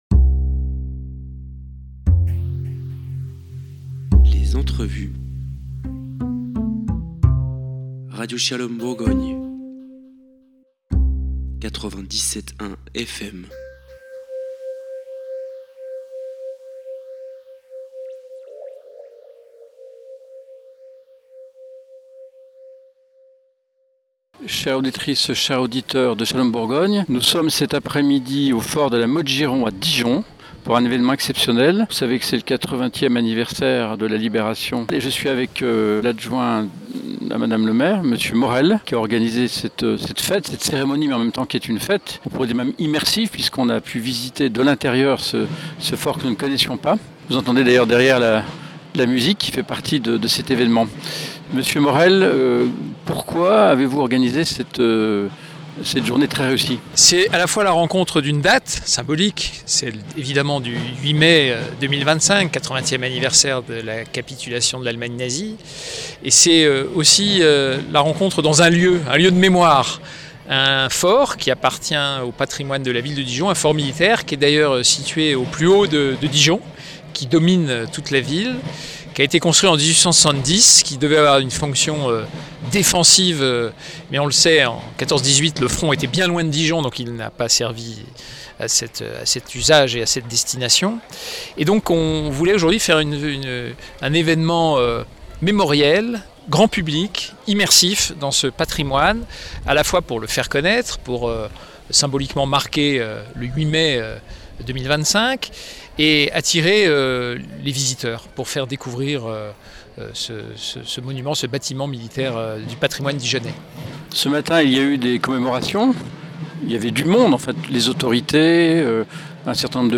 Nous avons pu interviewer Monsieur Jean-Philippe MOREL, adjoint de Madame Nathalie KOENDERS,
Entretien